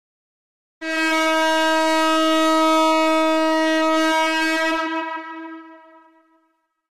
دانلود صدای بوق کشتی 2 از ساعد نیوز با لینک مستقیم و کیفیت بالا
جلوه های صوتی